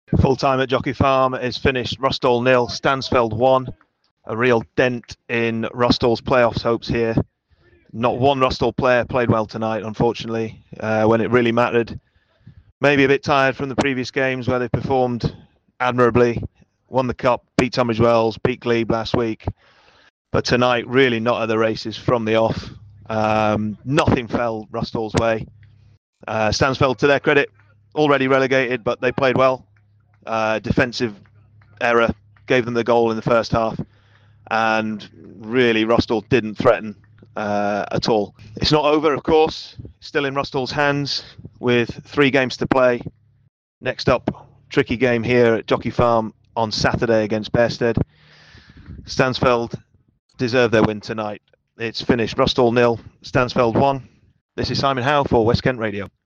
Full Time - Rusthall v Stansfeld